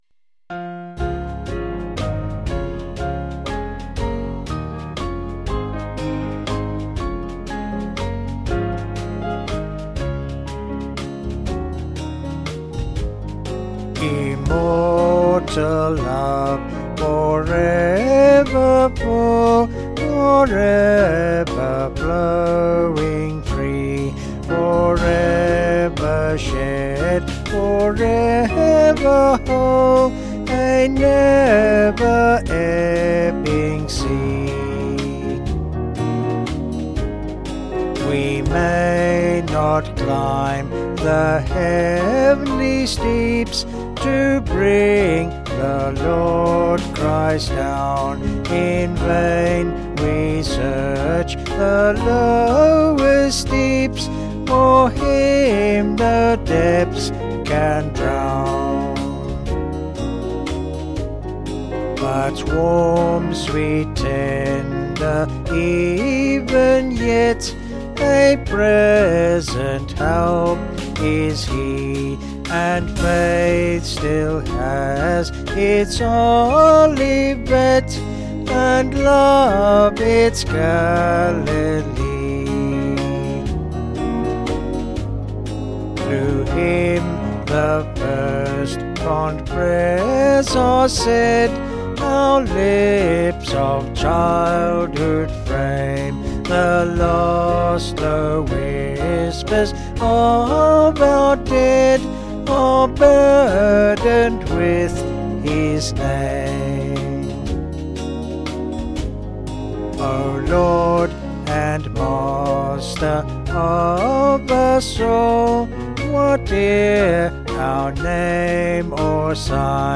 Small Band
Vocals and Band   263.1kb Sung Lyrics